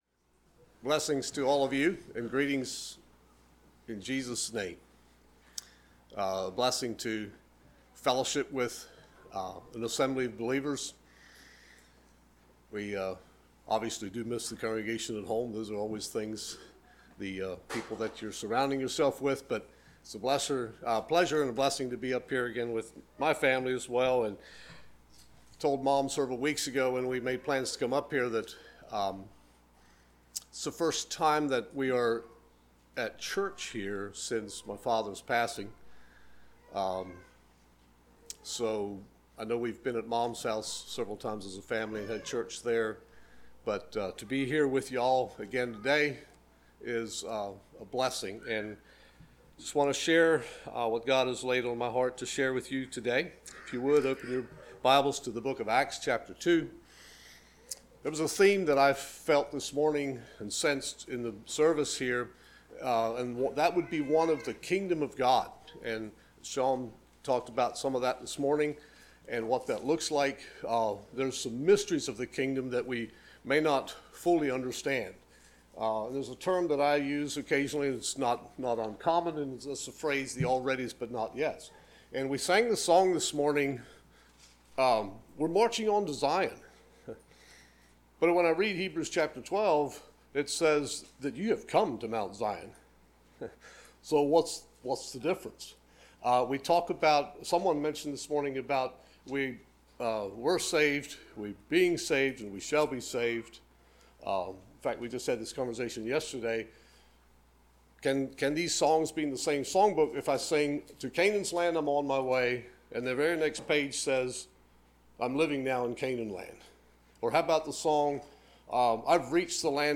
Service Type: Sunday Morning Topics: Being faithful , Church Life